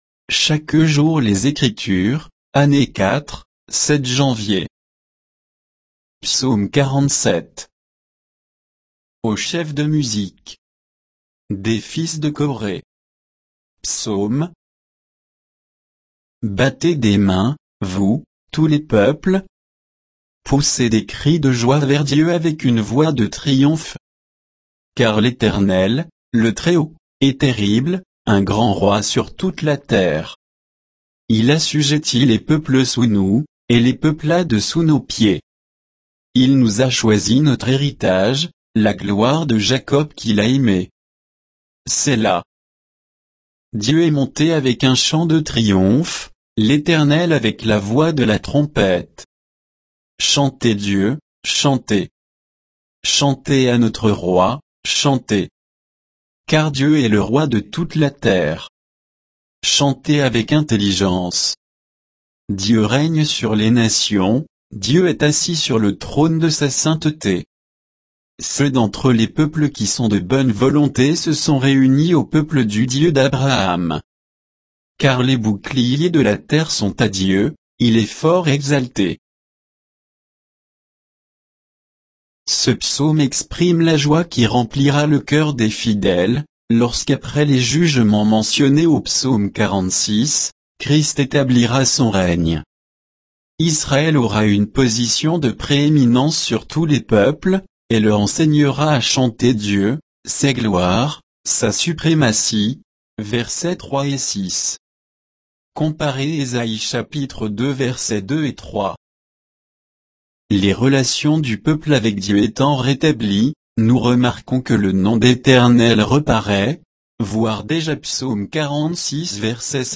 Méditation quoditienne de Chaque jour les Écritures sur Psaume 47